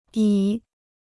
矣 (yǐ) Free Chinese Dictionary